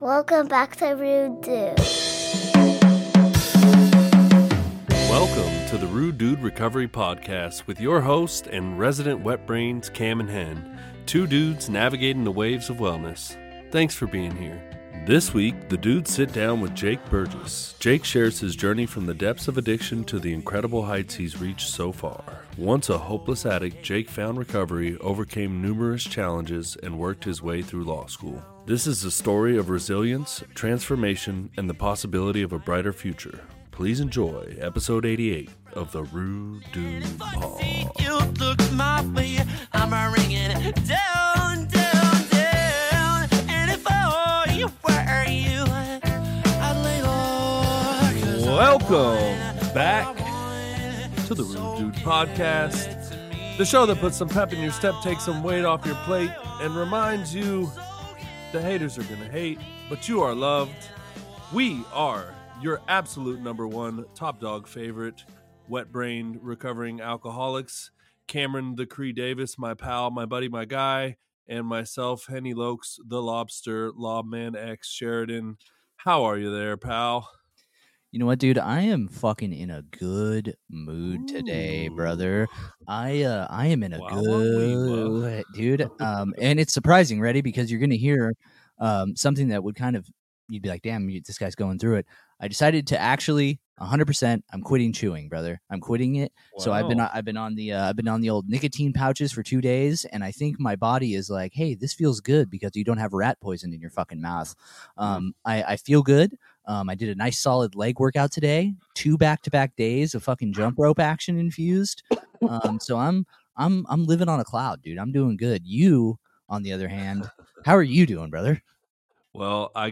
Don’t miss this inspiring conversation about perseverance, redemption, and hope.